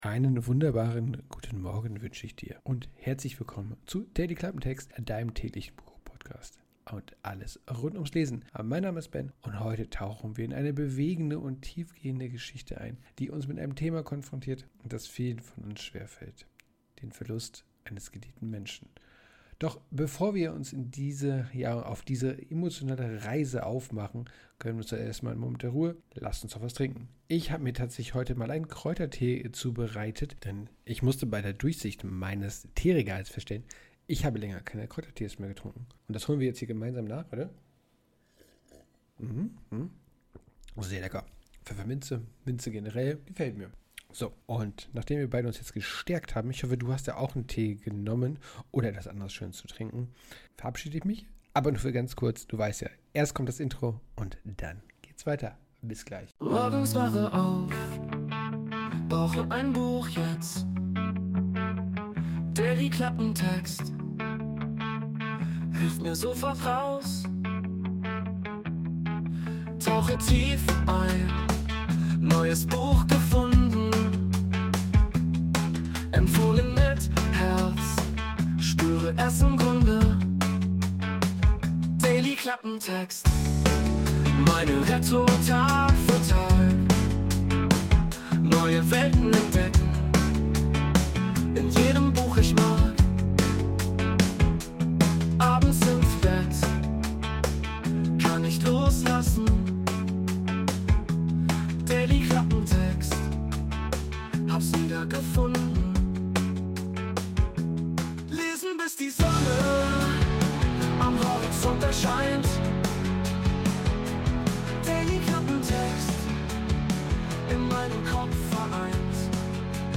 Athomosphärenmusik: Music by Mikhail Smusev from Pixabay
Intromusik: Wurde mit der KI Suno erstellt.